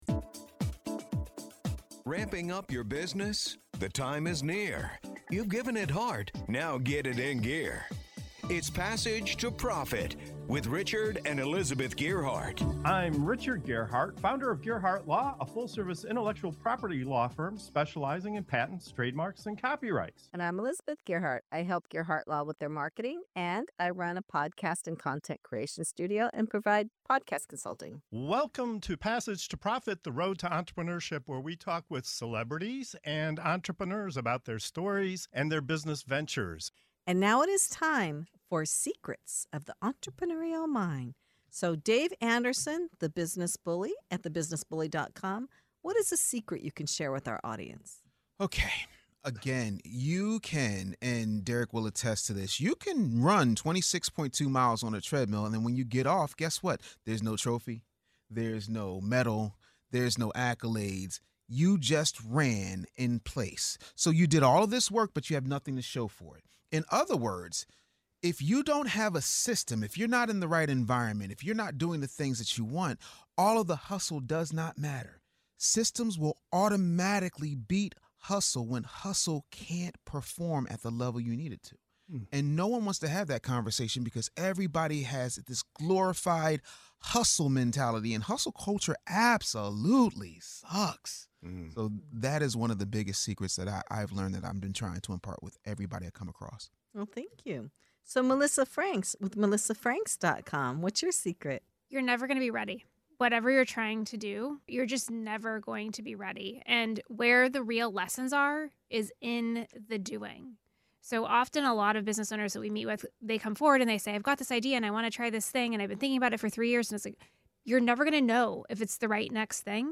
In this segment of "Secrets of the Entrepreneurial Mind" on Passage to Profit Show, our guests reveal the real secrets behind entrepreneurial success—from why hustle culture is a trap, to how systems, action, and deep purpose reshape your path. You’ll hear why waiting until you're “ready” keeps you stuck, how tapping into your legacy can fuel unstoppable motivation, and why embracing AI can reignite your business in surprising ways.